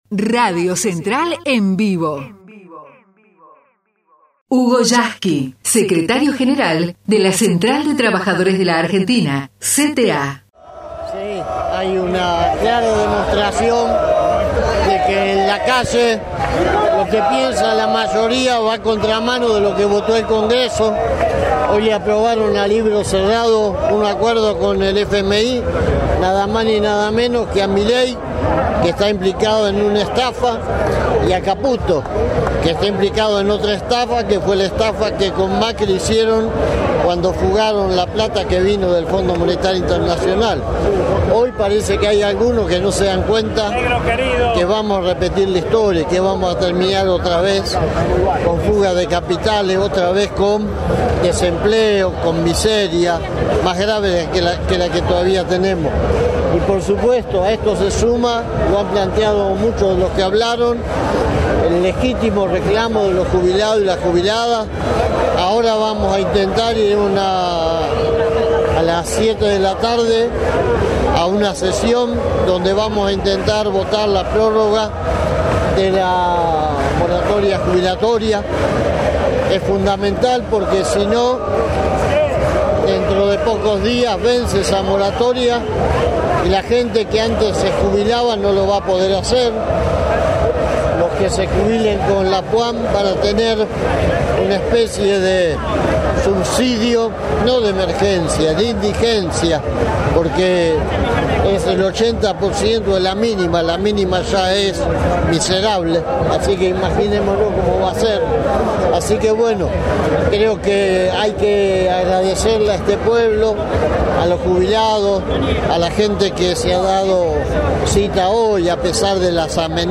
HUGO YASKY - JUBILAZO EN PLAZA CONGRESO